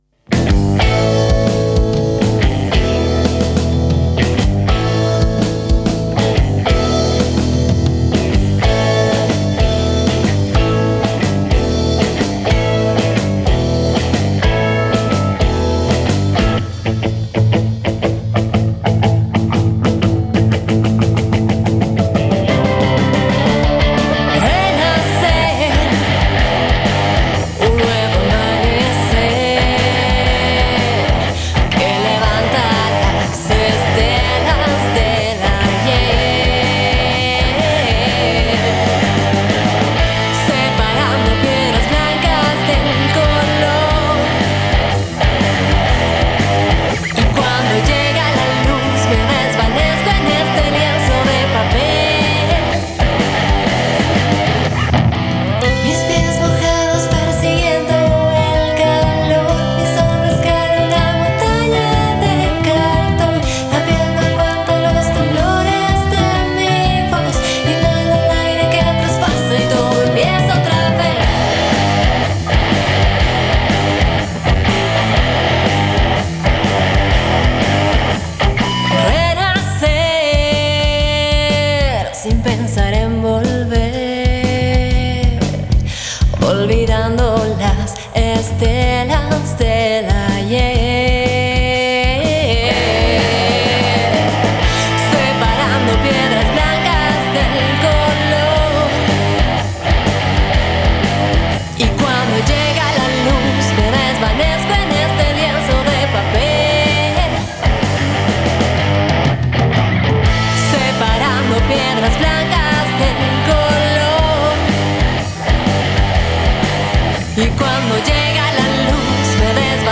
Banda, Fusión, Disco, Producción musical, Álbum